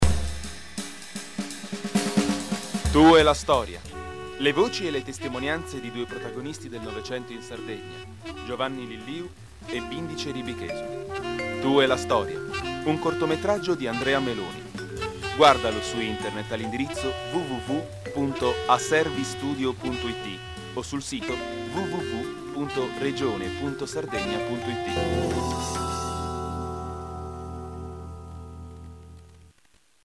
SPOT RADIO
spot RADIPRESS tu e la storia.mp3